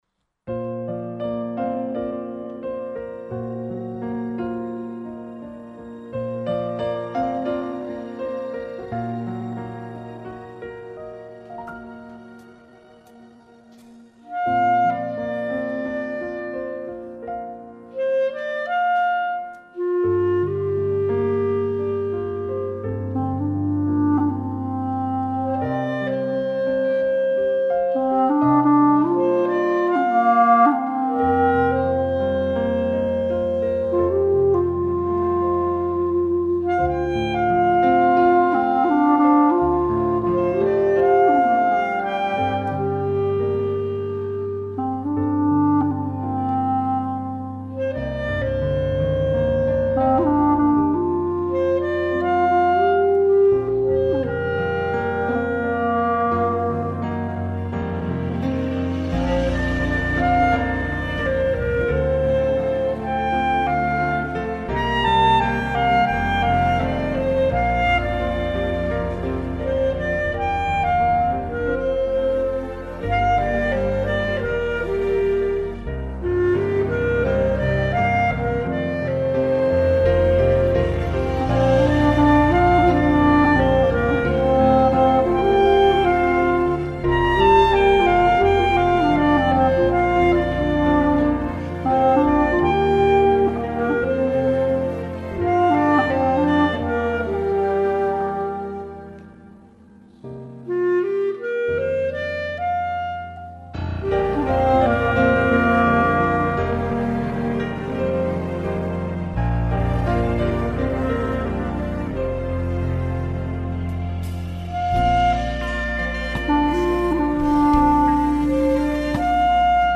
调式 : 降B